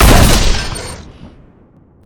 shoot3.ogg